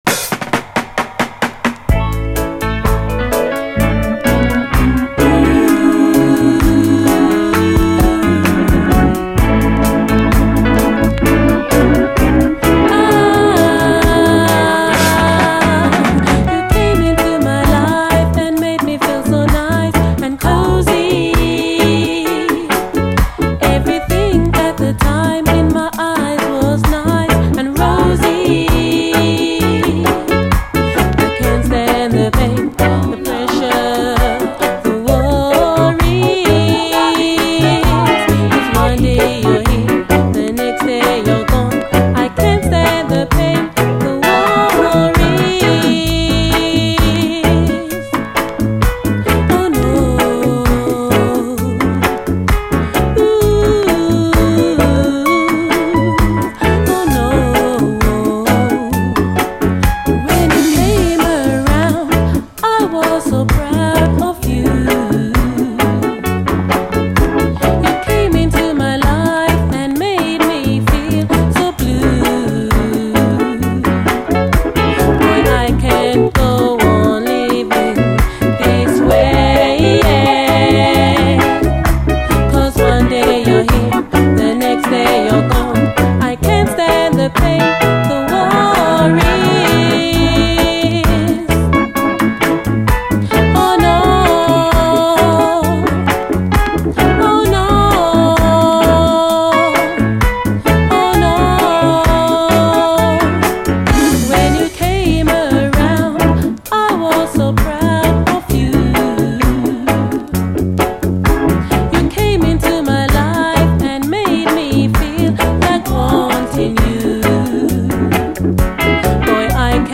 REGGAE
歌メロが非常に切なく美しい最高レアUKラヴァーズ！
後半はダブに接続。